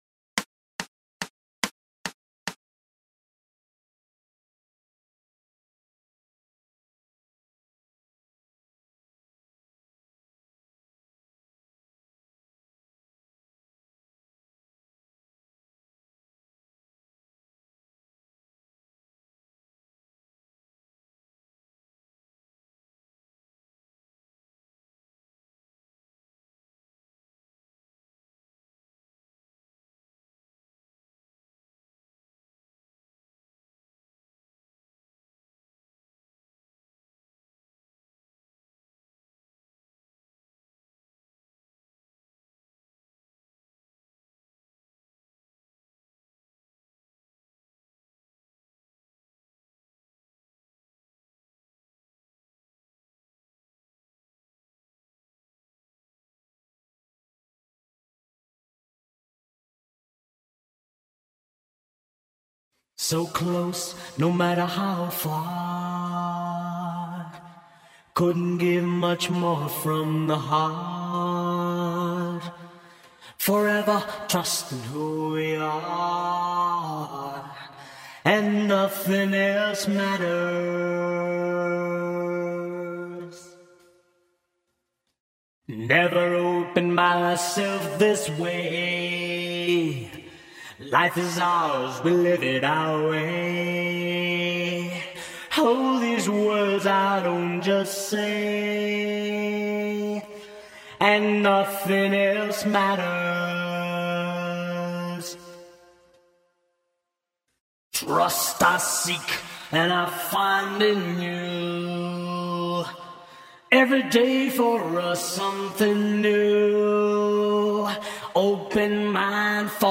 VOZ PRINCIPAL (Descargar)